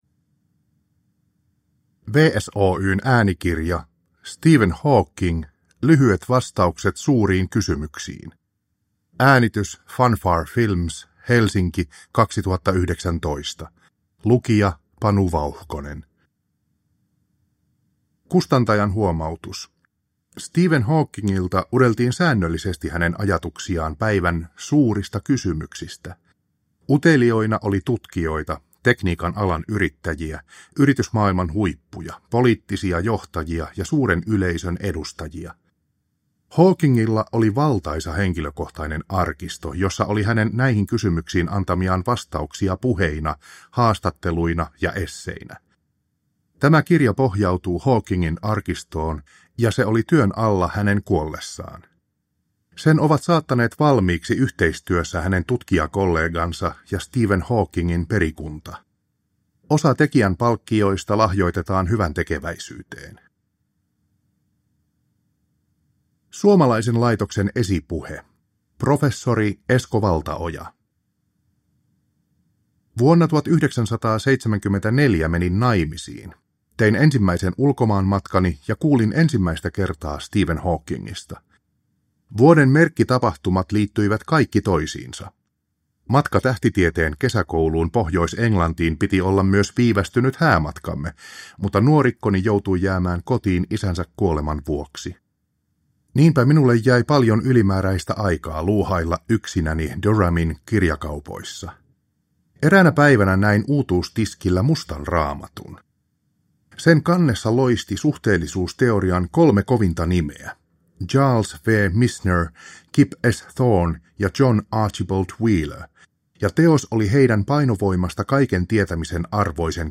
Lyhyet vastaukset suuriin kysymyksiin – Ljudbok – Laddas ner